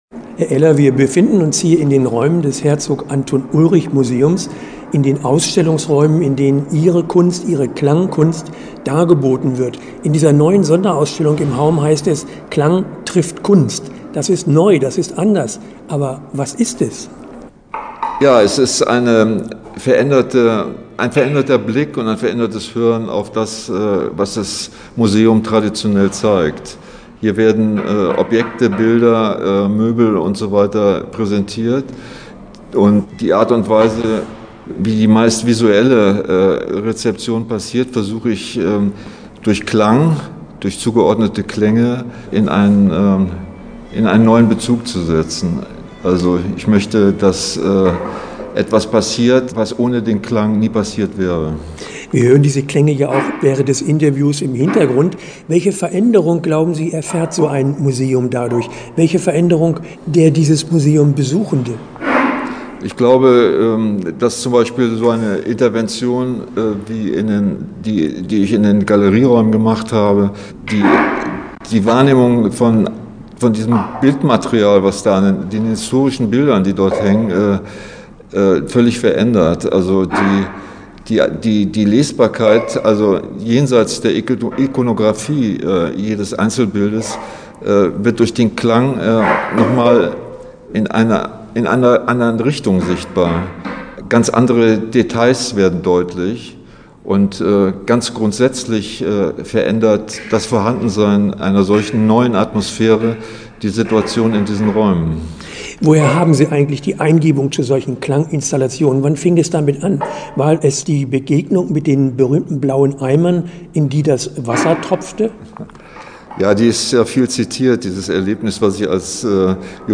Interview-Klang-trifft-Kunst-HAUM.mp3